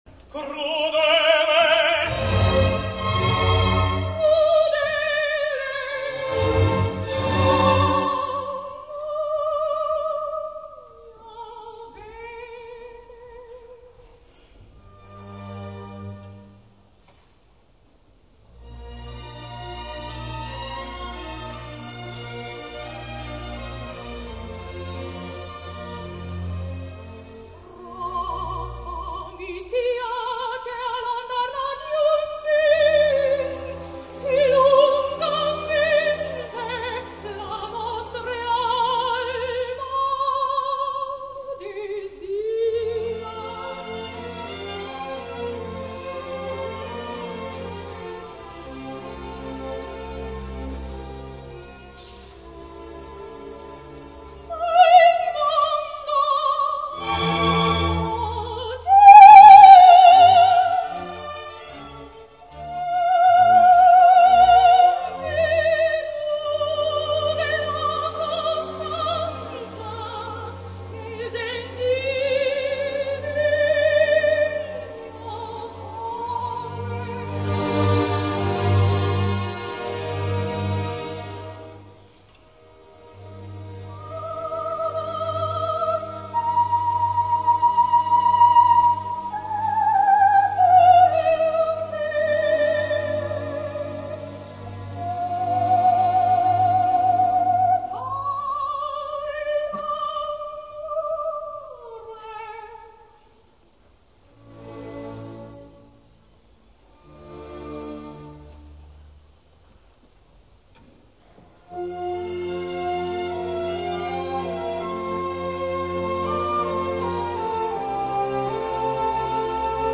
German soprano, 1911 - 1986